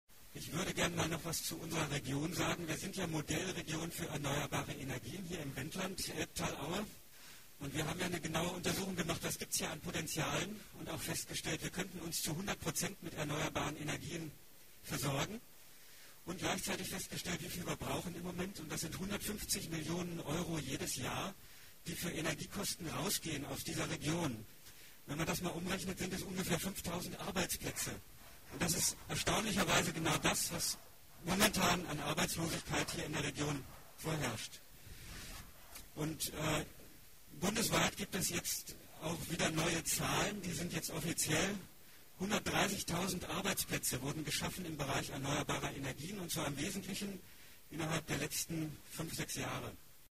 Solarwärme-Infotour in Dannenberg
Hier gibt es O-Ton zum herunterladen: